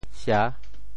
社 部首拼音 部首 礻 总笔划 7 部外笔划 3 普通话 shè 潮州发音 潮州 sia6 文 中文解释 社〈名〉 (會意。
sia6.mp3